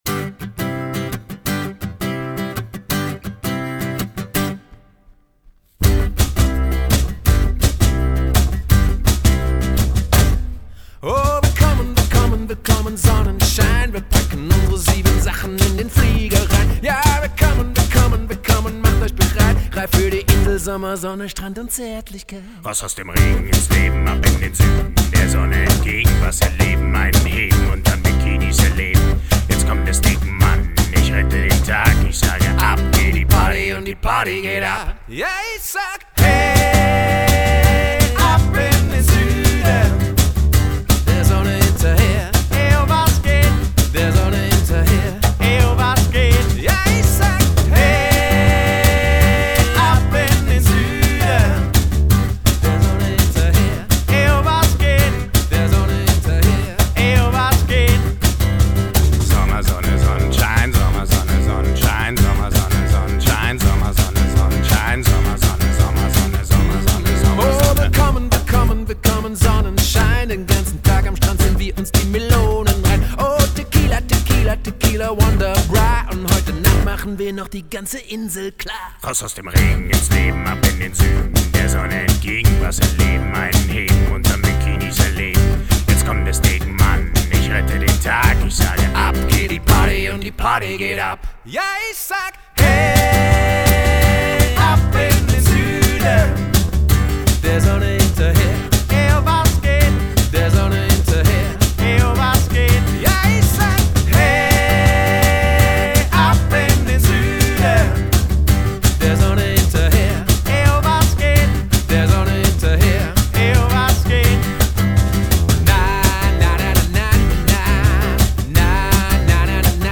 Acoustic Rock